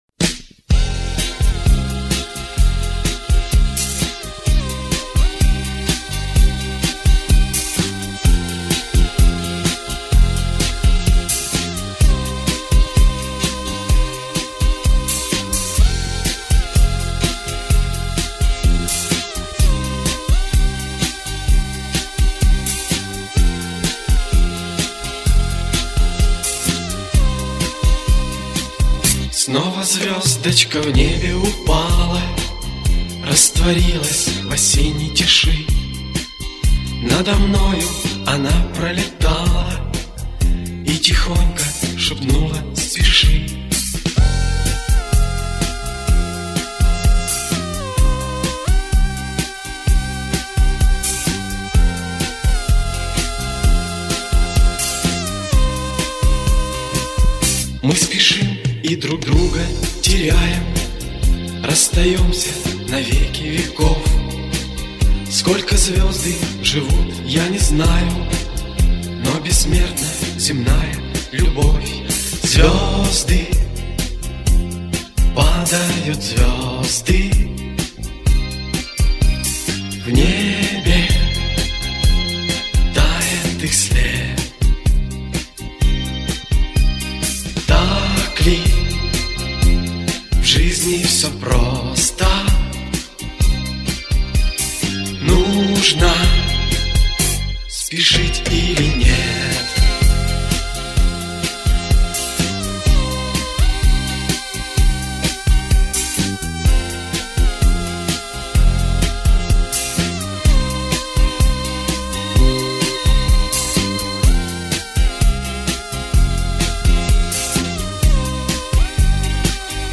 Вот качество получше.